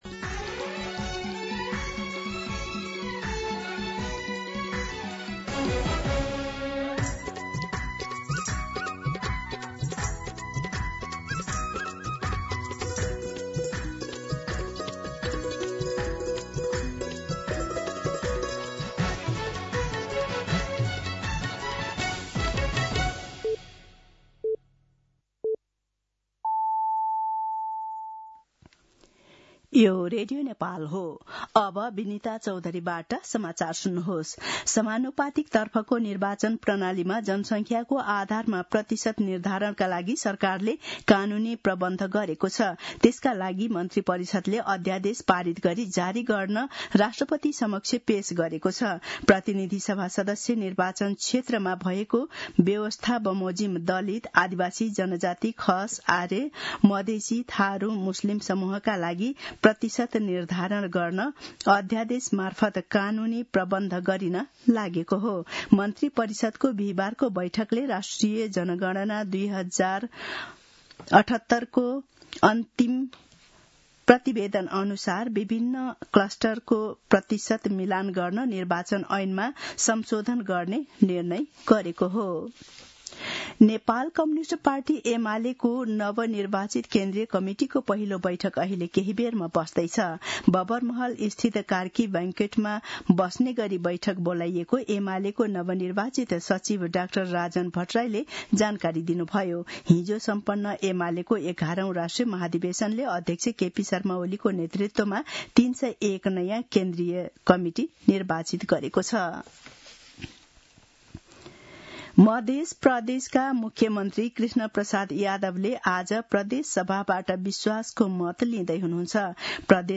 दिउँसो १ बजेको नेपाली समाचार : ४ पुष , २०८२